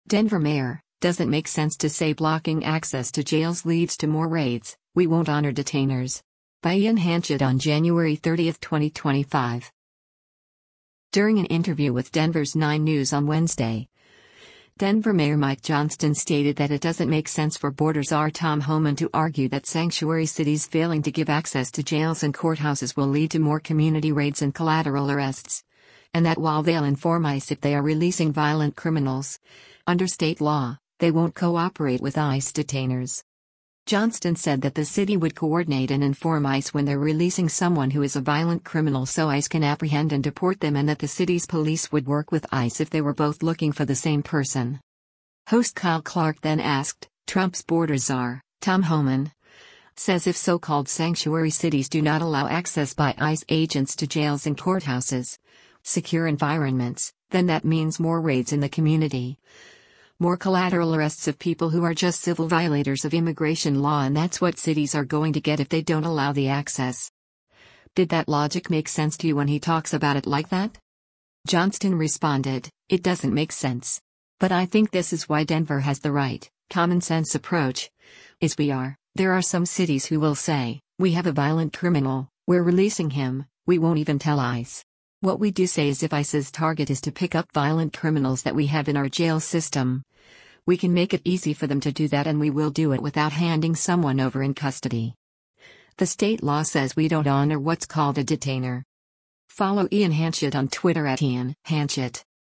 During an interview with Denver’s 9News on Wednesday, Denver Mayor Mike Johnston stated that “It doesn’t make sense” for Border Czar Tom Homan to argue that sanctuary cities failing to give access to jails and courthouses will lead to more community raids and collateral arrests, and that while they’ll inform ICE if they are releasing violent criminals, under state law, they won’t cooperate with ICE detainers.